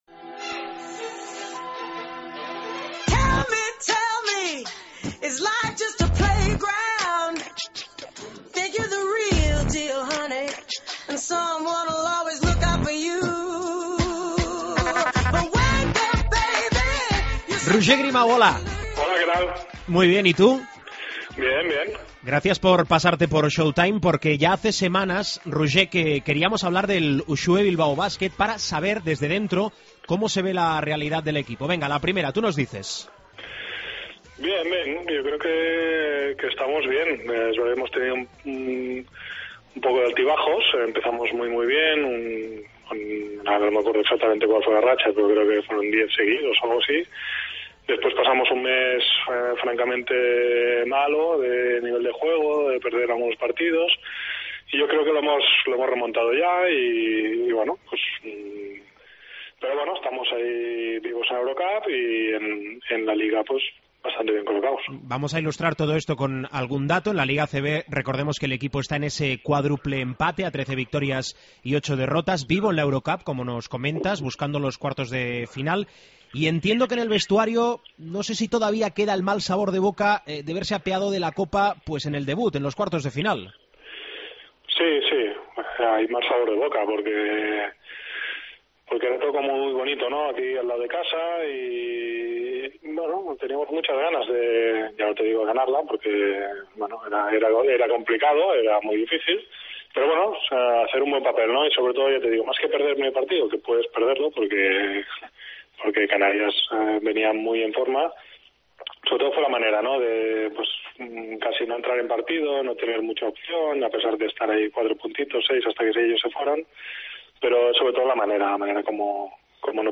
Entrevista a Roger Grimau